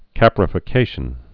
(kăprə-fĭ-kāshən)